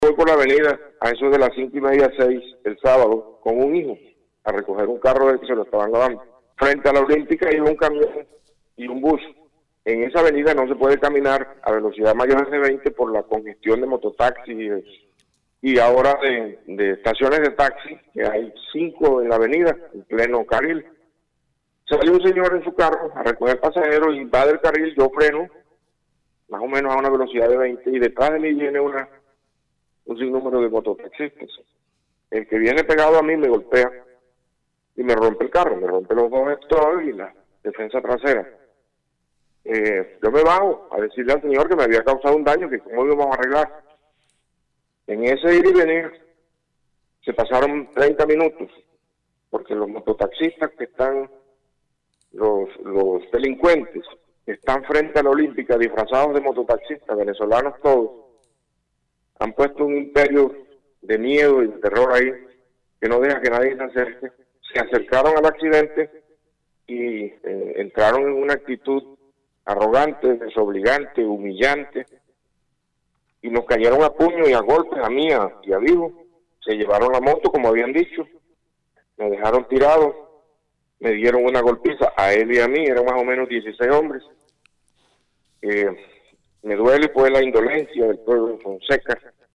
Escuche el relato del exalcalde Luis Emiro Peralta sobre la golpiza que recibió en pleno centro de Fonseca.